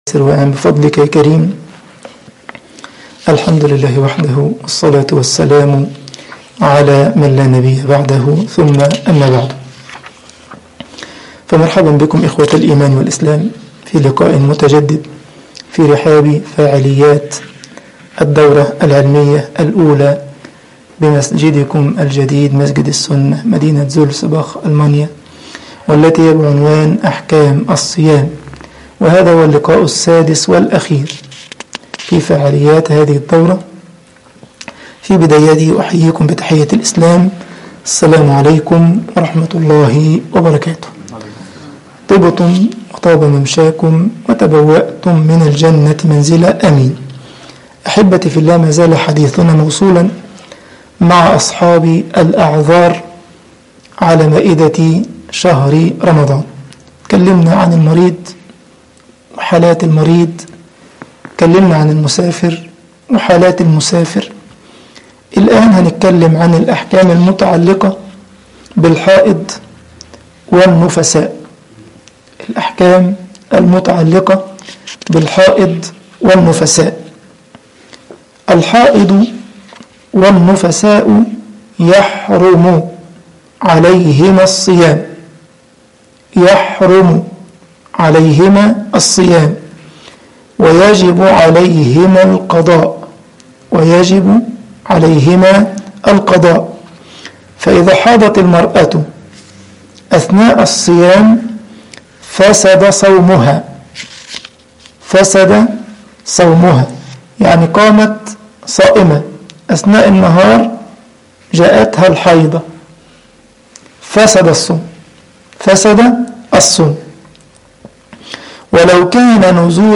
الدورة العلمية رقم 1 أحكام الصيام المحاضرة رقم 6